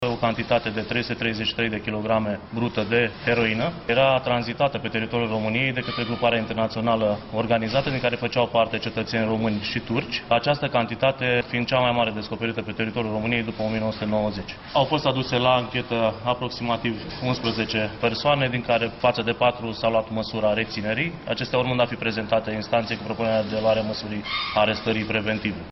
2 români și 2 turci au fost reținuți – a anunțat procurorul șef al DIICOT, Daniel Horodniceanu.